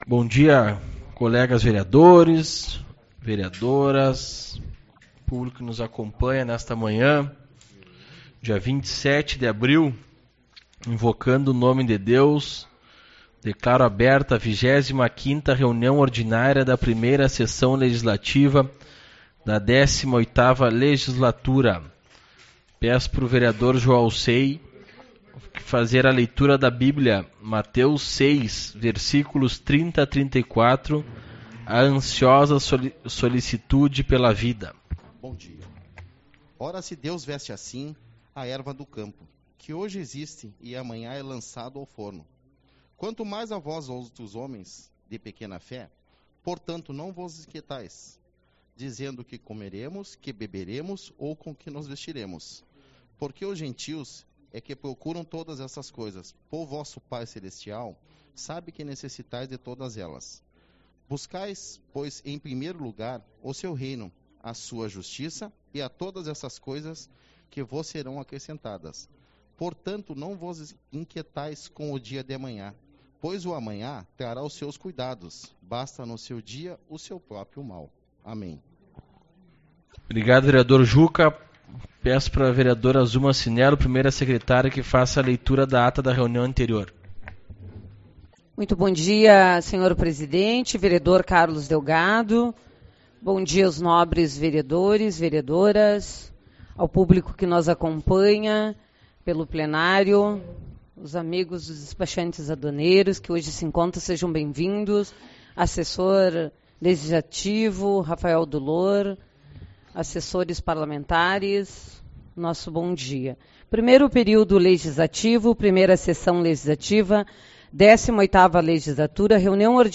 27/04 - Reunião Ordinária